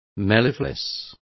Complete with pronunciation of the translation of mellifluous.